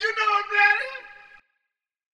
Vox (ImReady).wav